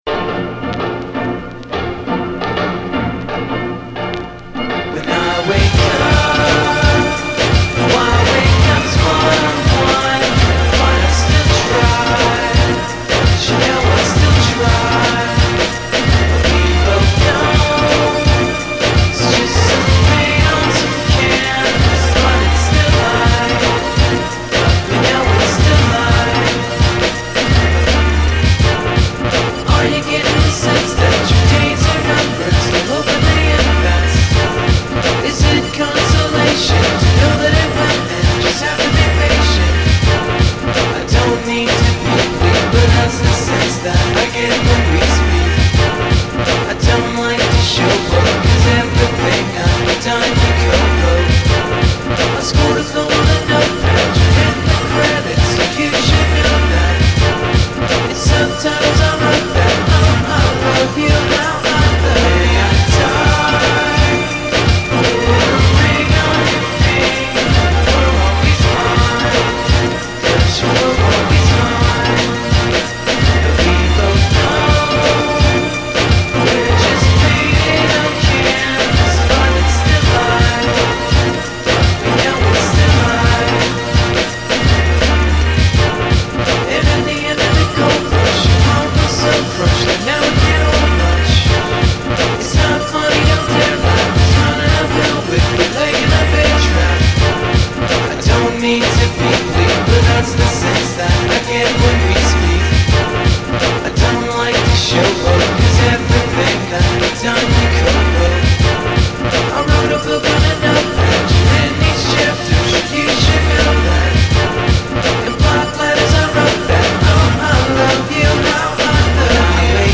Category: indietronica
Their music has been defined as lo-fi and indie-electronica.